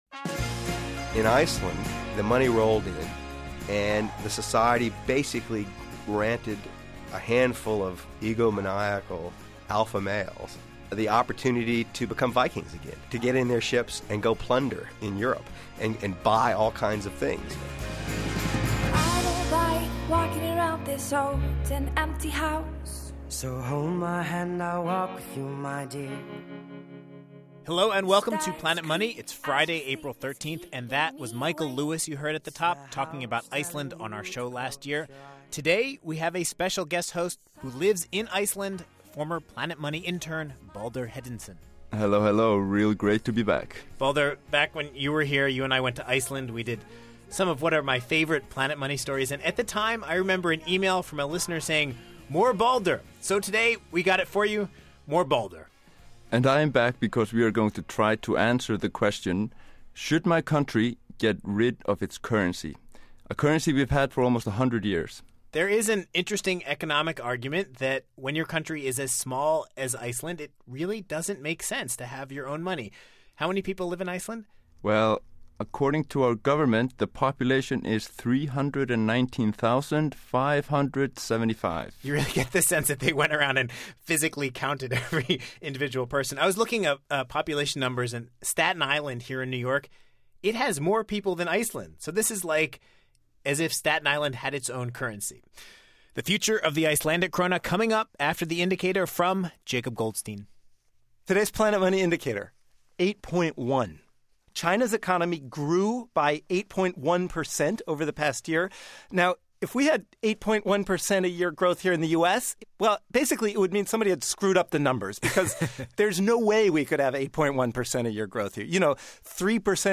And we talk to Robert Mundell, who won a Nobel for working on questions like the one Iceland is facing.